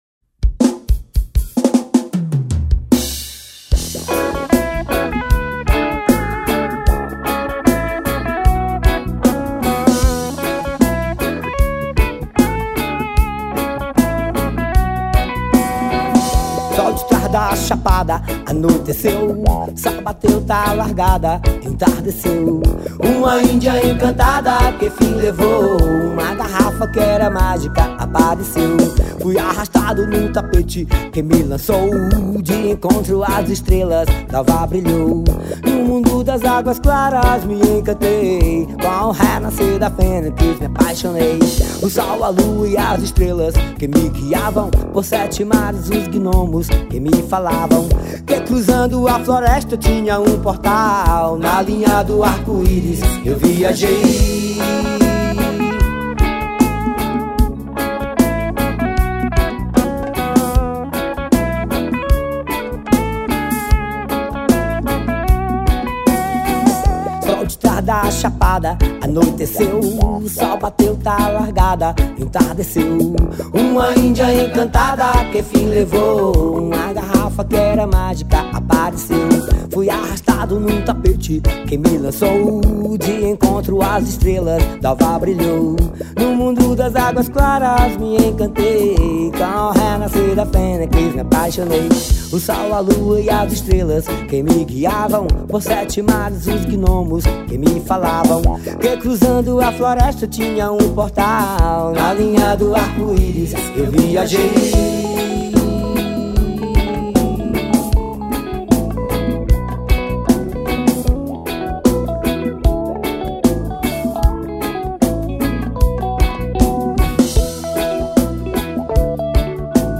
2237   03:44:00   Faixa:     Forró